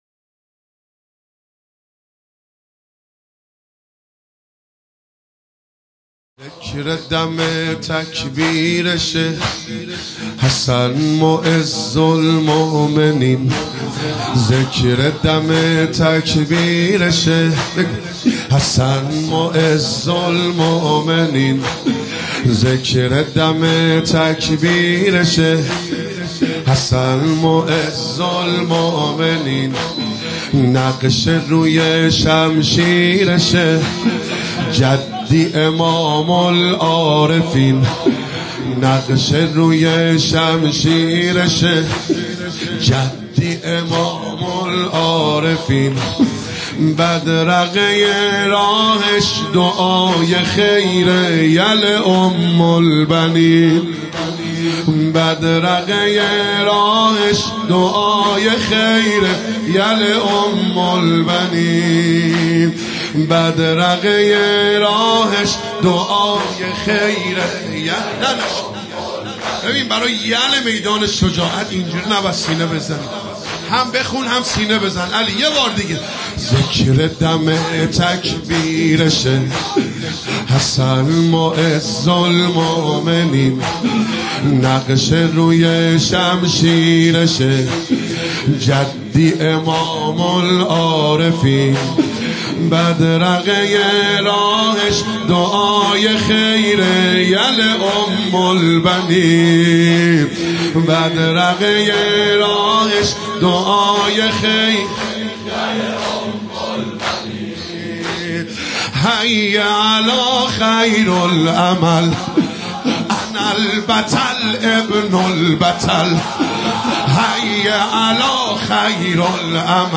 زمینه | ذکر دم تکبیرشِ، حسن معزالمومنین
مداحی
در شب ششم محرم 1397 | هیأت یافاطمه الزهرا (سلام الله علیها) بابل